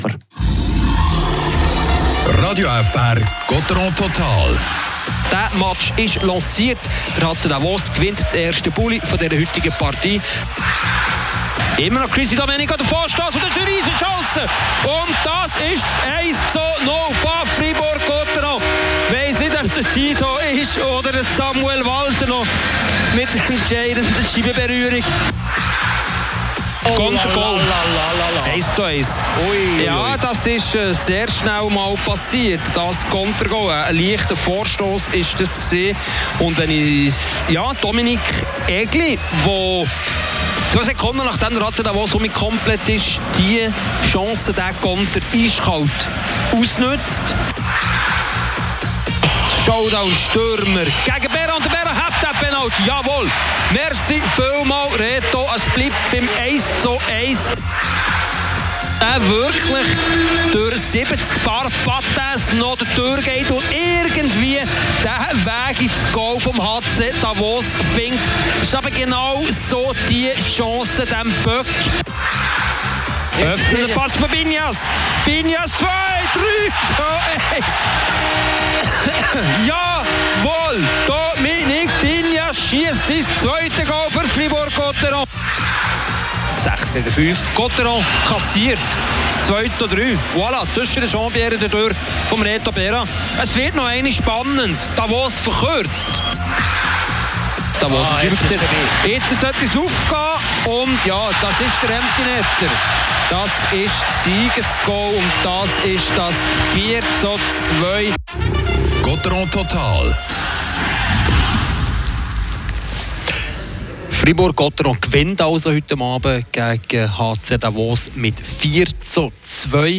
Die Freiburger gewinnen am Sonntagabend gegen Davos 4:2. Hier gibts die Highlights kommentiert